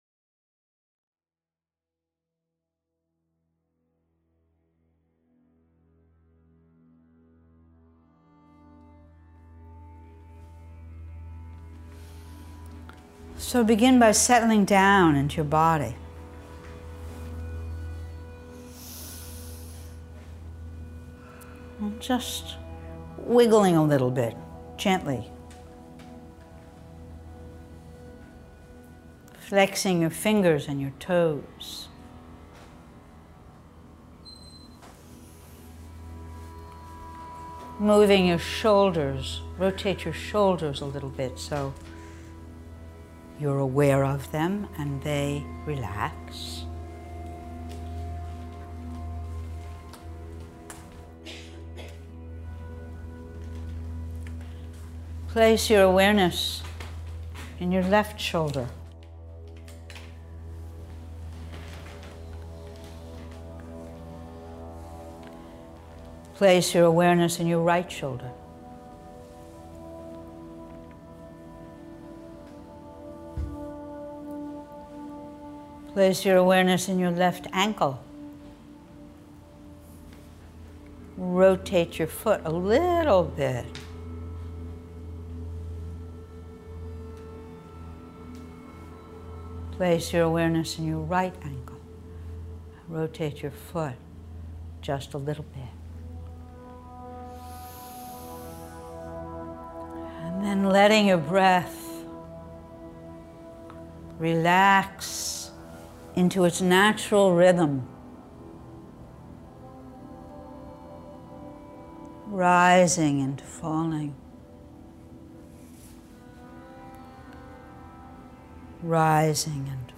They are recorded live in class.